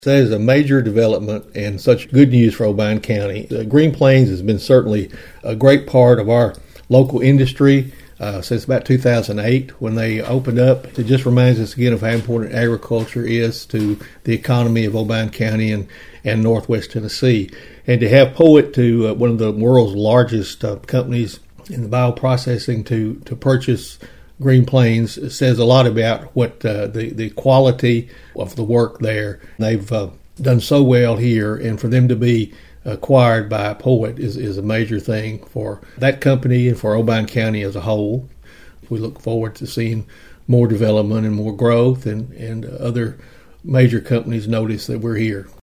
Obion County Mayor Steve Carr praised the long partnership with Green Plains, and welcomed POET into the local business community.(AUDIO)